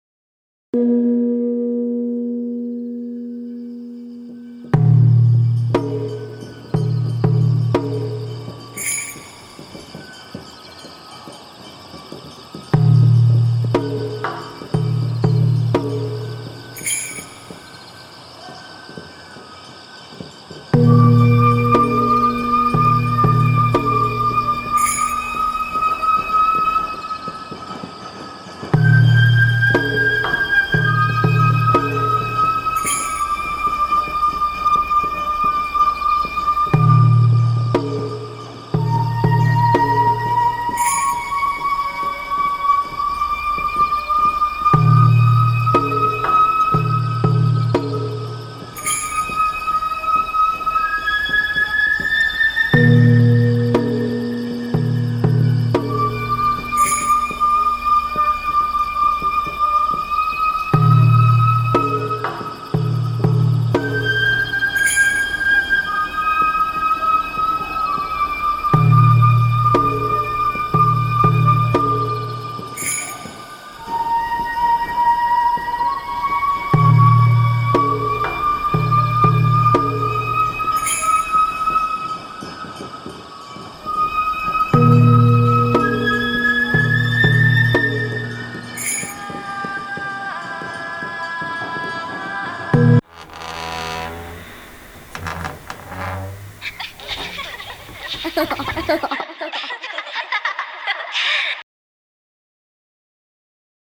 笑い声
「祭の情景･･･SEだけのトラック を入れたい」というアイディアをはじめ聞いた時は驚きました。